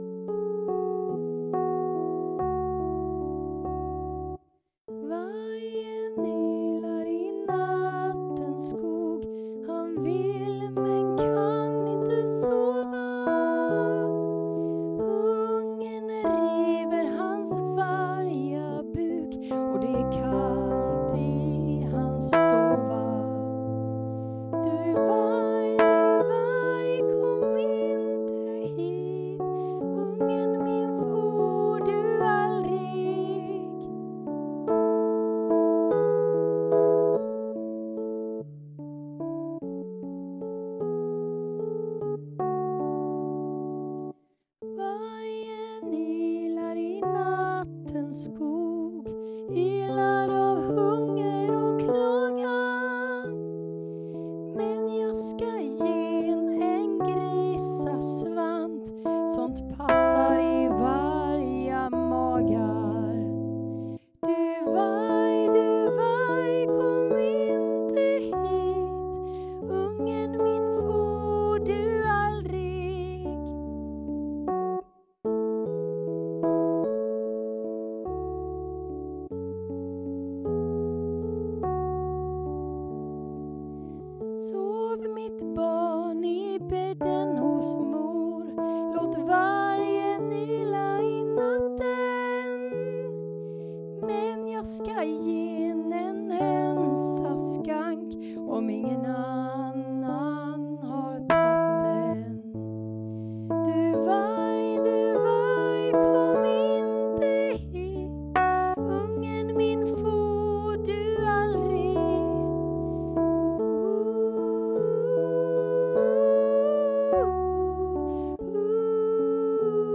Här kommer en inspelning med sång i Am där jag sjunger i barnens oktav: